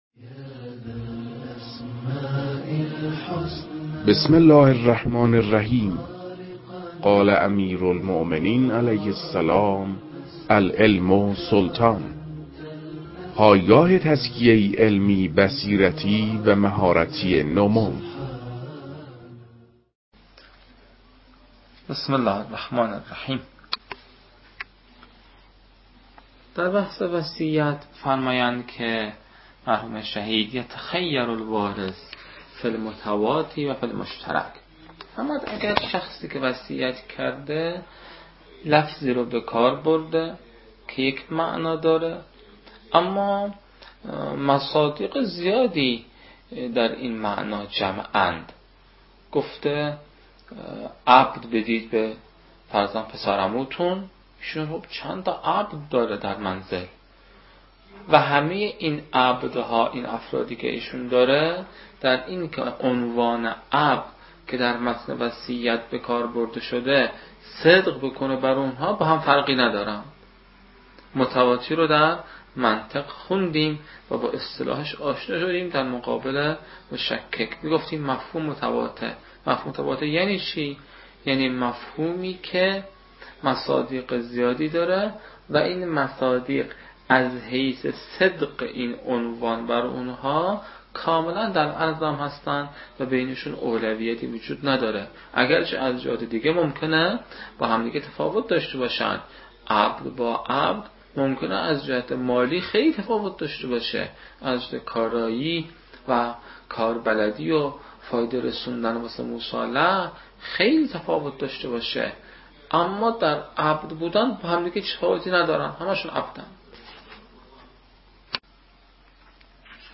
این فایل، تدریس بخشی از کتاب شرح لمعه (الروضة البهية في شرح اللمعة الدمشقية (المحشى - كلانتر)، ج‌5، ص: 39)